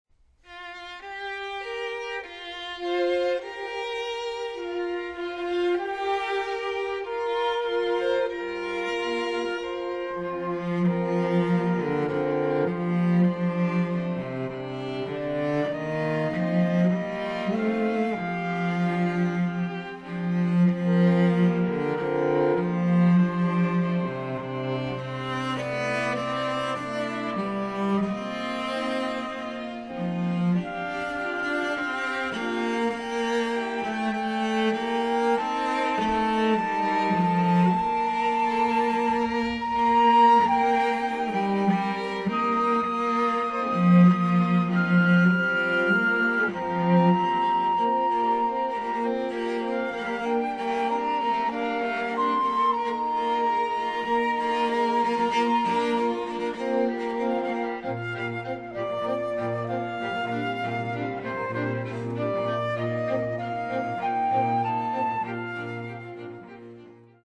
(Flute, Violin, Viola and Cello)
(Two Violins, Viola and Cello)